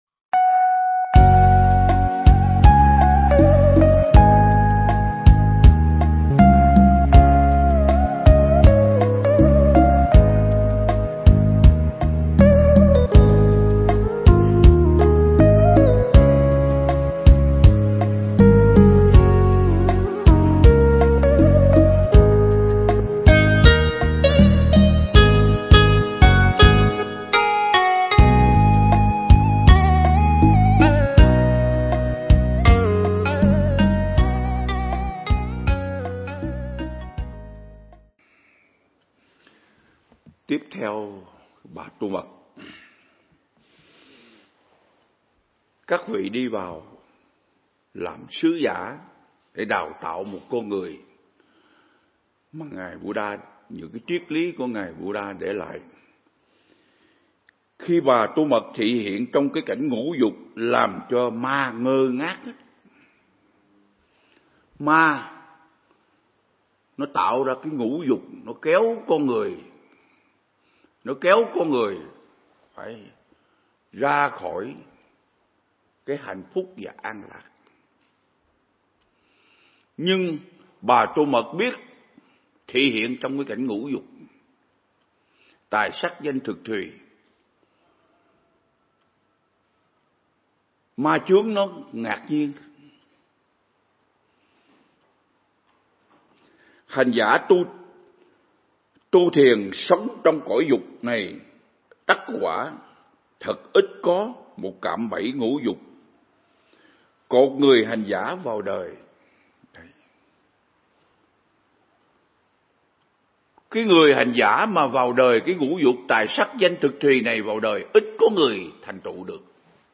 Np3 Pháp Thoại Ứng Dụng Triết Lý Hoa Nghiêm Phần 52
giảng tại Viện Nghiên Cứu Và Ứng Dụng Buddha Yoga Việt Nam (TP Đà Lạt)